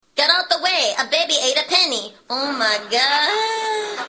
MP3 Custom Car Horns and Ringtones (Showing 20 Results)